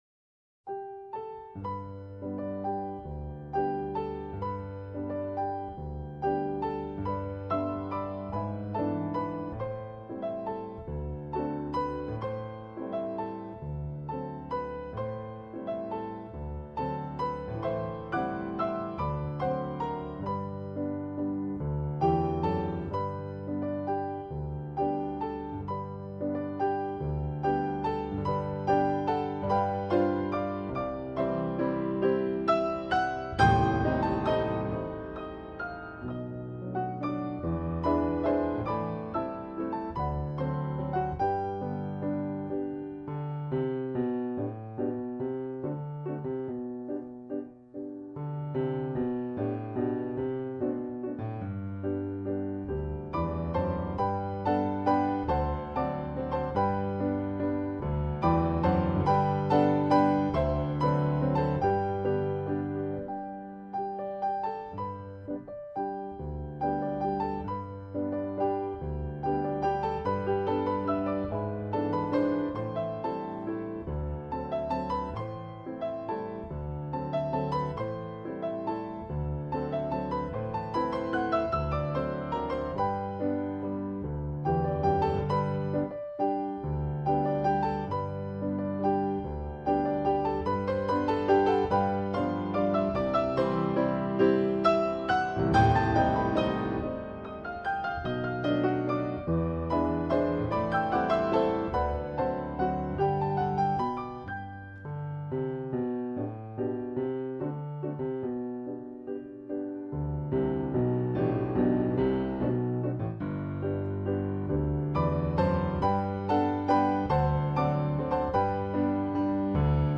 เปียโน
เพลงพระราชนิพนธ์, เปียโน